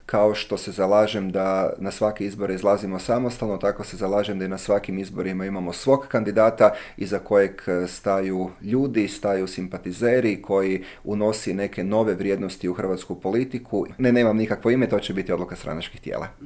ZAGREB - Međimurski župan Matija Posavec, nositelj HNS-ove liste za europske izbore, u razgovoru za Media servis otkrio je zašto ustraje na samostalnom izlasku, predstavlja li HNS-u vodstvo uteg, planira li zasjesti na mjesto predsjednika stranke i zašto nije bio za ulazak u vladajuću koaliciju.